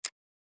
دانلود آهنگ کلیک 37 از افکت صوتی اشیاء
جلوه های صوتی
دانلود صدای کلیک 37 از ساعد نیوز با لینک مستقیم و کیفیت بالا